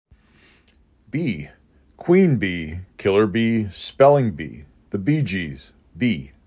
3 Letters, 1 Syllable
b E